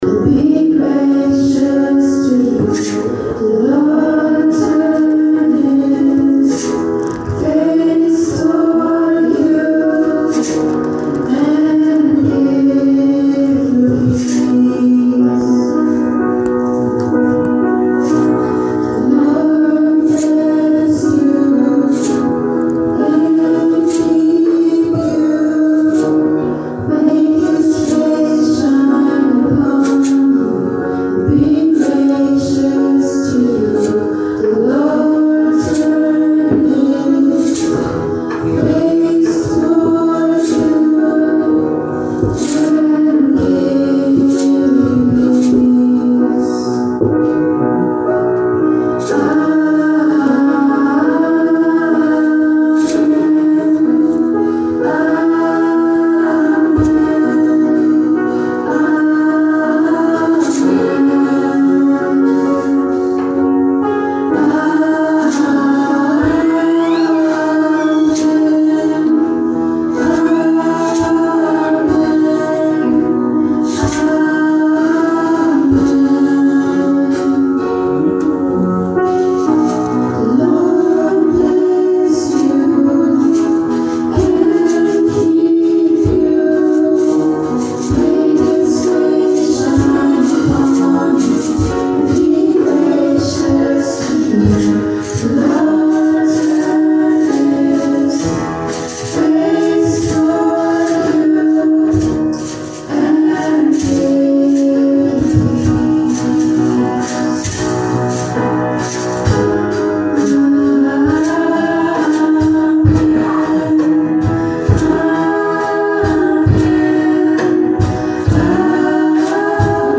Fellowship Service, February 13, 2022.
voice
drums
piano
shaker.
February_13_service_music2.mp3